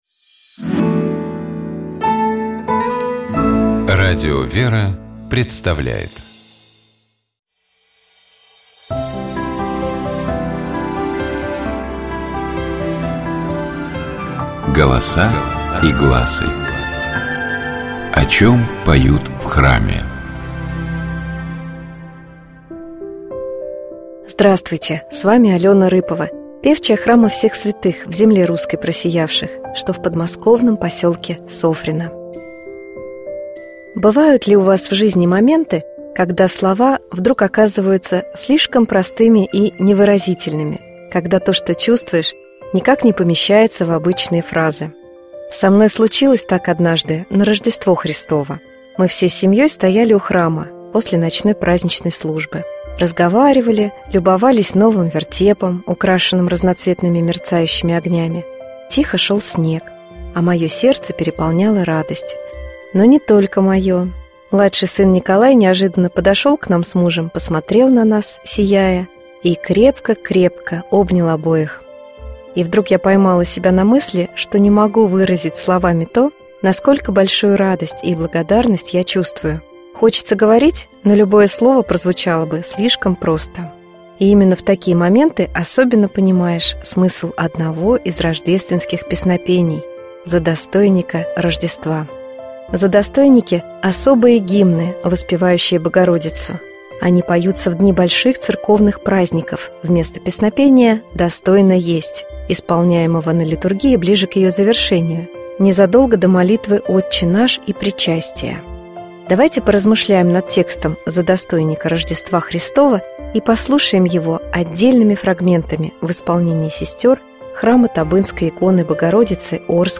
Давайте поразмышляем над текстом задостойника Рождества Христова и послушаем его отдельными фрагментами в исполнении сестёр храма Табынской иконы Богородицы Орской епархии.